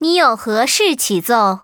文件 文件历史 文件用途 全域文件用途 Erze_tk_02.ogg （Ogg Vorbis声音文件，长度0.0秒，0 bps，文件大小：22 KB） 源地址:游戏语音 文件历史 点击某个日期/时间查看对应时刻的文件。